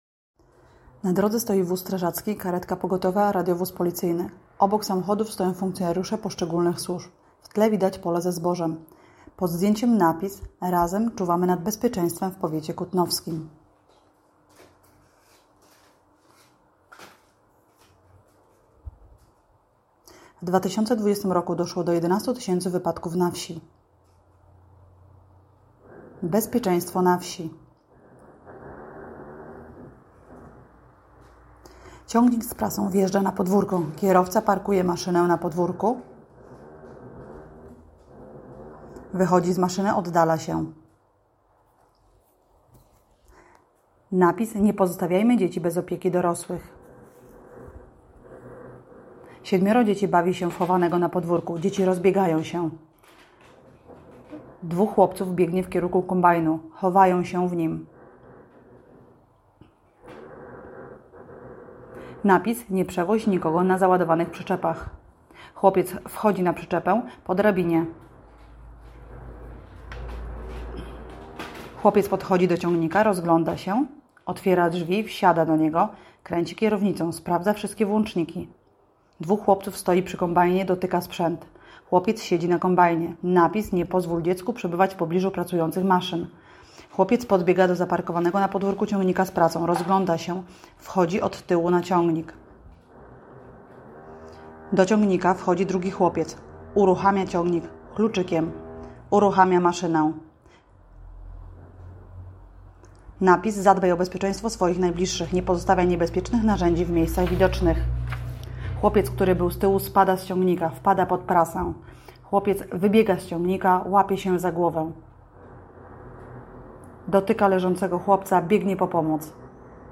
Audiodeskrypcja spotu - plik mp3